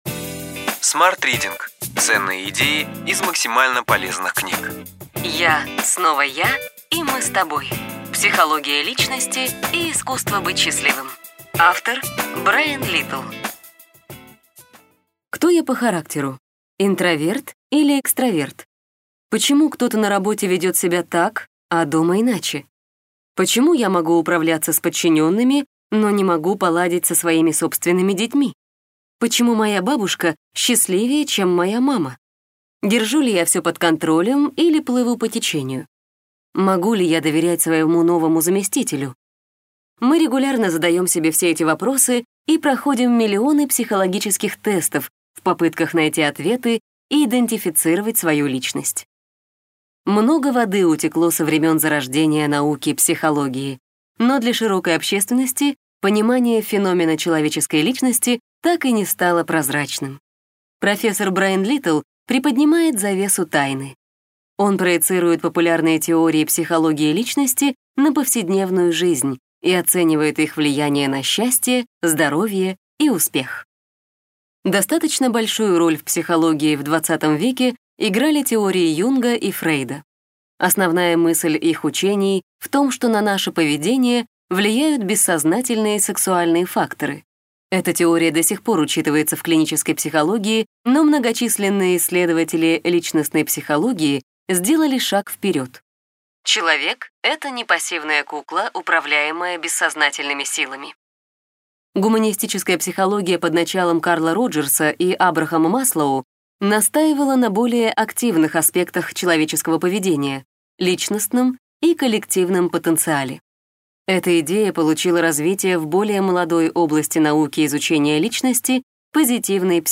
Аудиокнига Ключевые идеи книги: Я, снова я и мы с тобой: психология личности и искусство быть счастливым.